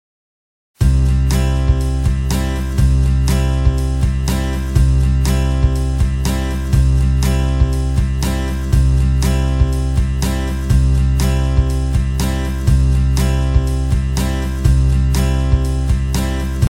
Sempre serão duplas de MAIOR-MENOR ou então de MENOR-MAIOR com a mesma tônica e mesma levada.
maior-ou-menor-8.mp3